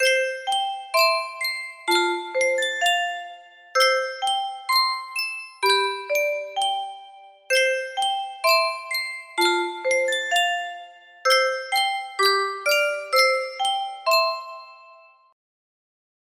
Yunsheng Music Box - Nearer My God to Thee 3985 music box melody
Full range 60